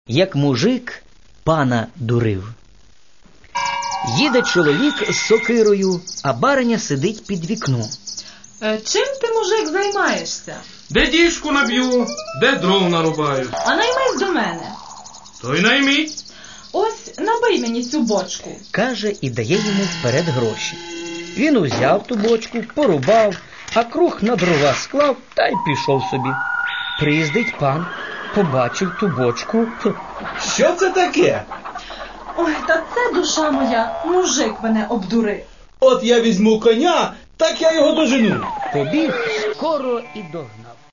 И не просто так начитанных – а рассказанных ярко, образно, эмоционально (и правда, хорошо здесь поработали актеры юмористического театра "КУМ"). Еще и с музыкальным сопровождением – как же без музыки?